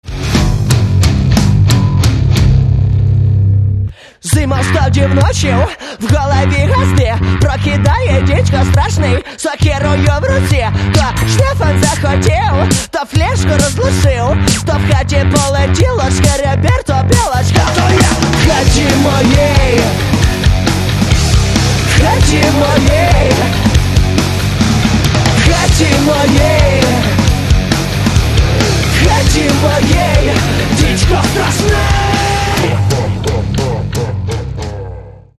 Catalogue -> Rock & Alternative -> Energy Rock